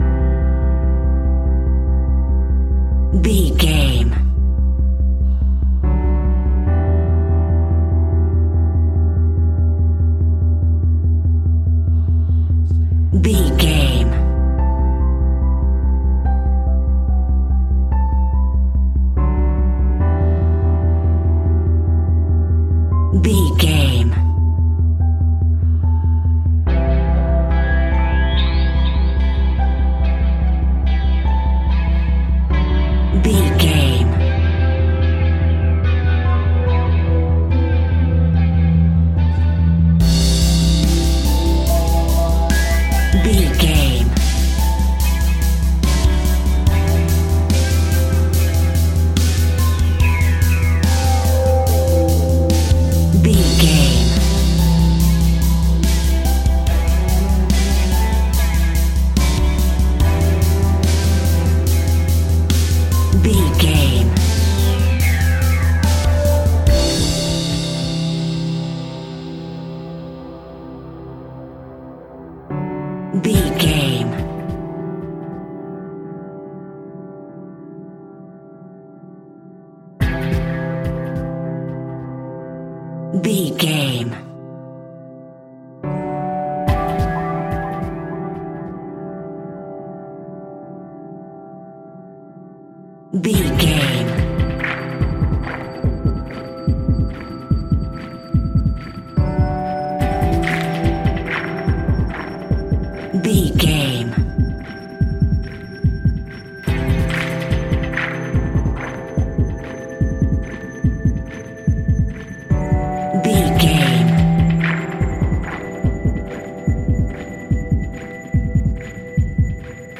In-crescendo
Aeolian/Minor
tension
ominous
dark
haunting
eerie
strings
synth
ambience
pads
eletronic